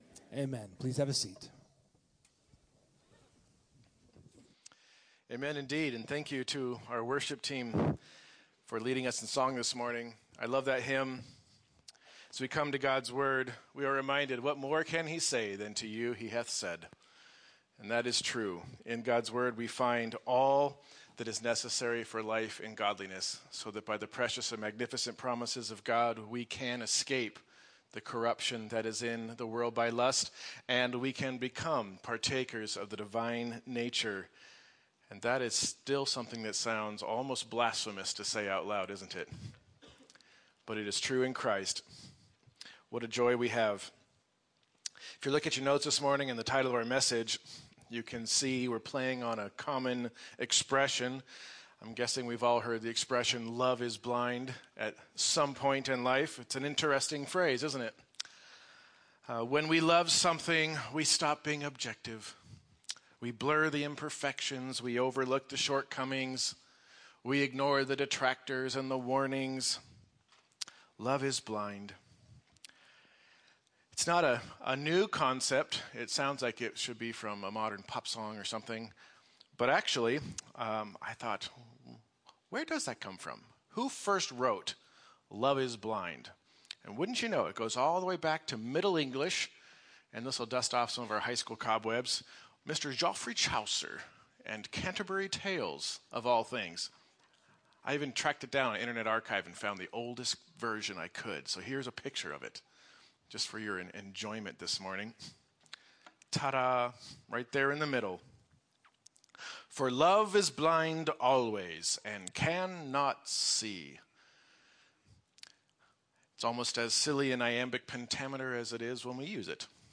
Feb. 22’s Sunday service livestream, bulletin/sermon notes/Life Group questions, the online Connection Card, and playlists of Sunday’s music (Spotify and YouTube).